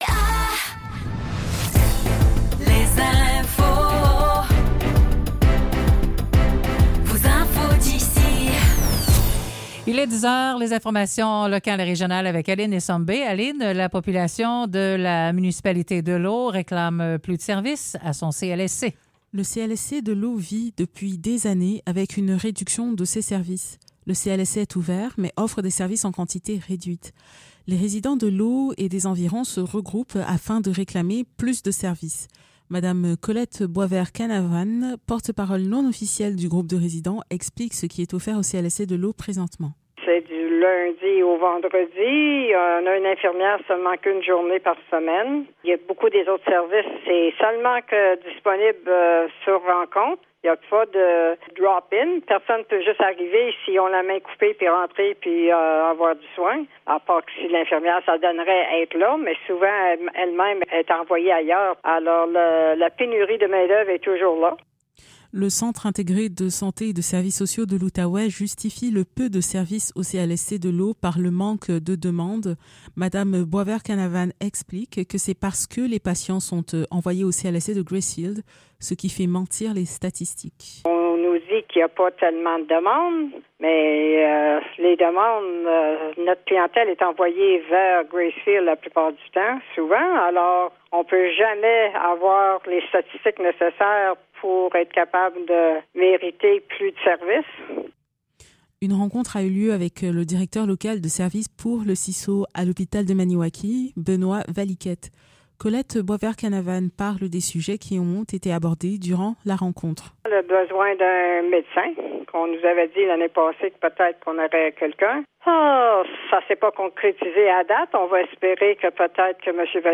Nouvelles locales - 13 mars 2024 - 10 h